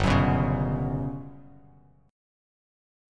GM#11 chord